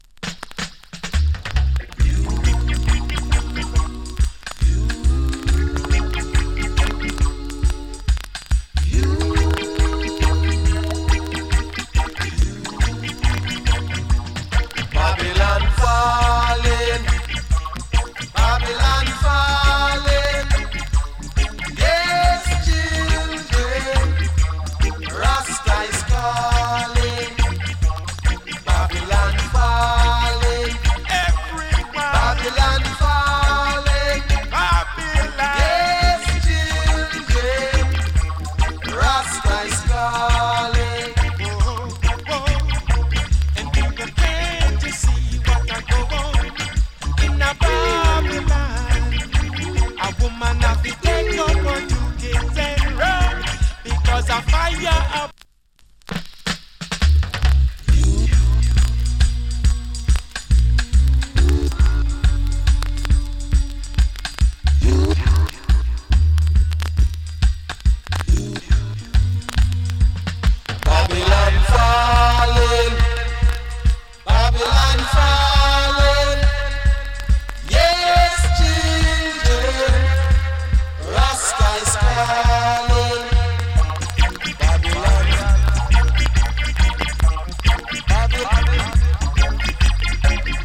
チリ、パチノイズ多数有り。
の GREAT ROOTS VOCAL !